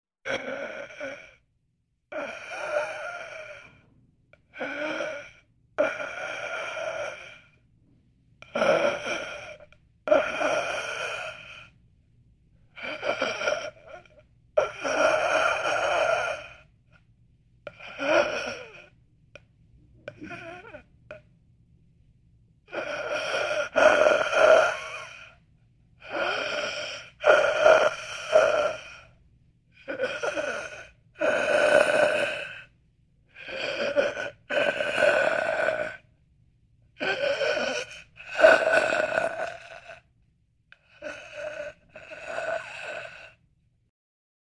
Человек задыхается
Тут вы можете прослушать онлайн и скачать бесплатно аудио запись из категории «Анатомия, тело человека».